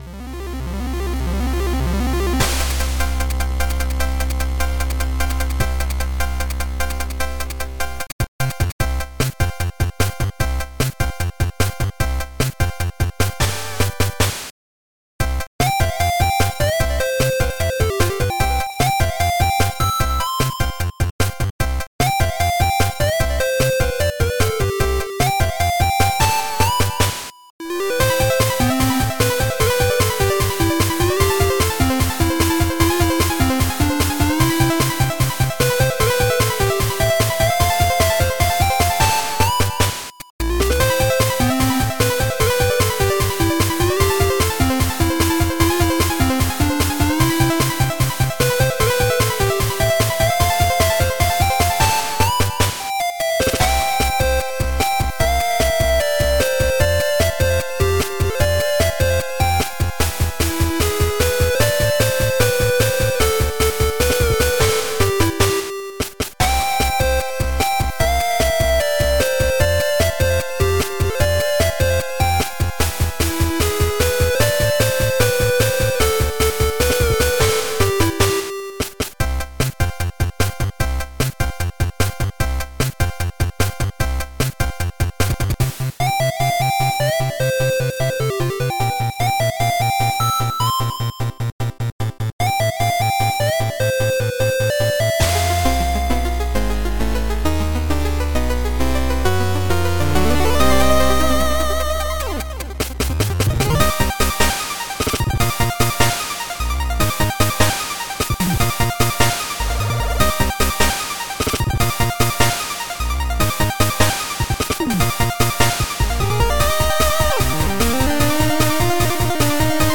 oldschool (chiptune